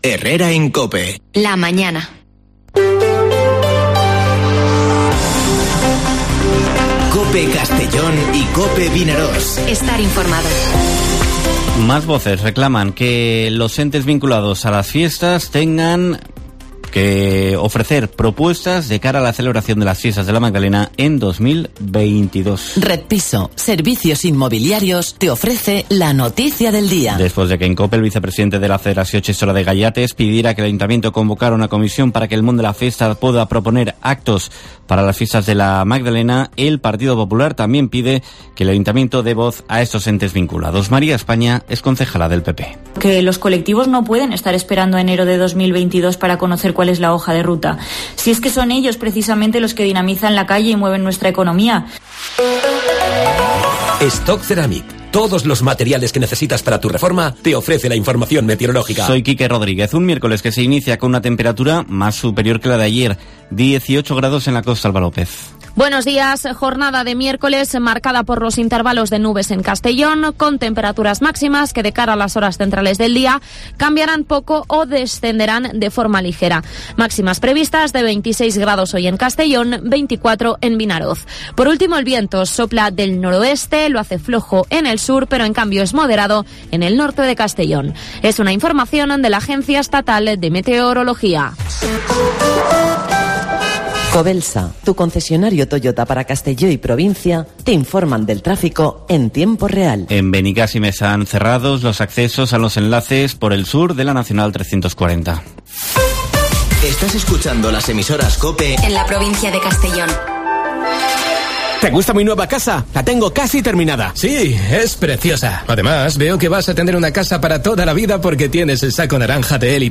Informativo Herrera en COPE en la provincia de Castellón (06/10/2021)